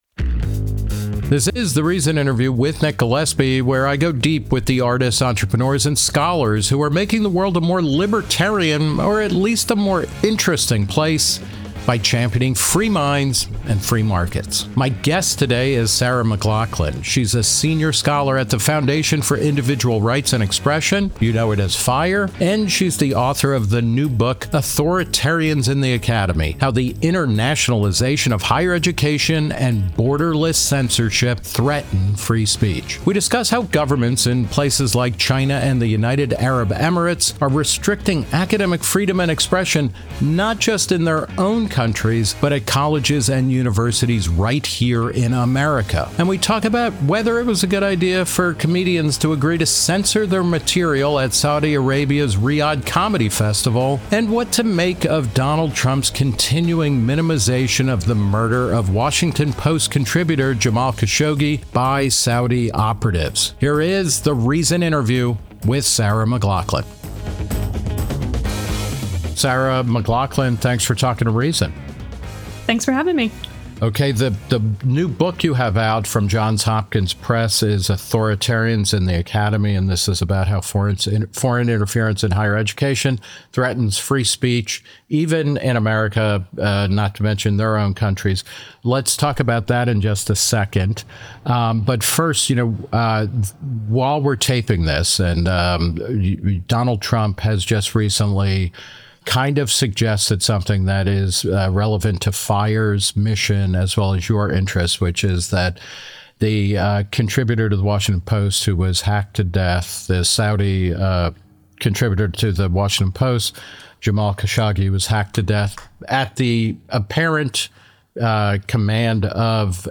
The Reason Interview With Nick Gillespie Podcastok
1 What We Get Wrong About the American Revolution 1:03:29 Play Pause 14d ago 1:03:29 Play Pause Lejátszás később Lejátszás később Listák Tetszik Kedvelt 1:03:29 Today's guest is Ken Burns, the filmmaker who has massively reshaped national conversations about everything from the Civil War to baseball to jazz to immigration to national parks with epic documentary series that have aired on public television.